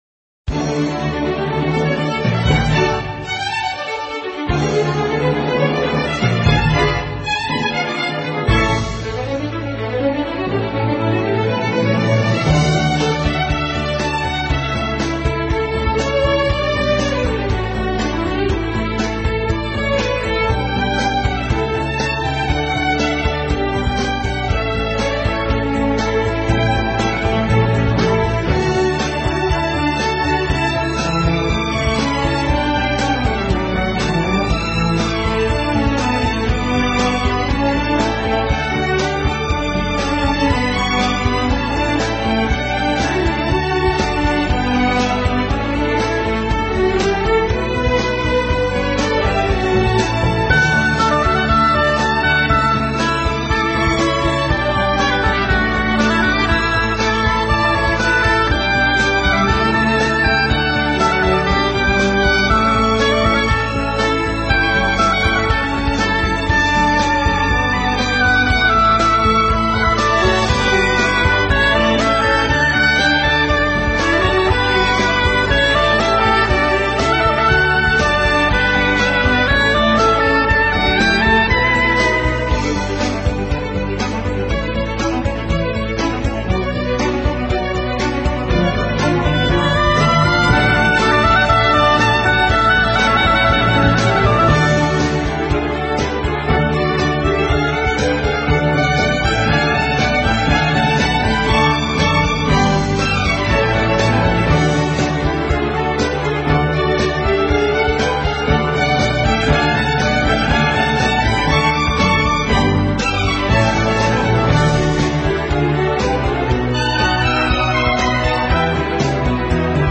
音乐风格： 其他|古典|Neo Classical，室内乐